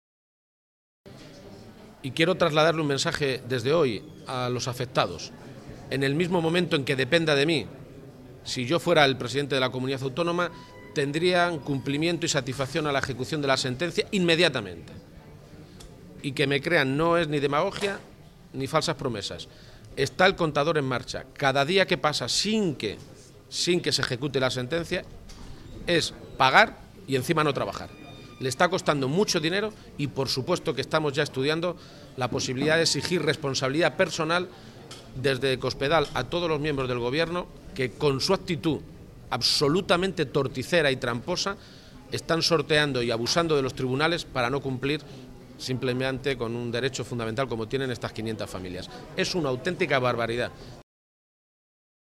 García-Page se pronunciaba de esta manera esta mañana, en Toledo, a preguntas de los medios de comunicación, y aprovechaba para añadir que los socialistas están evaluando si, además de exigir esa readmisión inmediata al actual Ejecutivo, caben emprender más acciones para pedir responsabilidades personales a Cospedal y su Consejo de Gobierno.
Cortes de audio de la rueda de prensa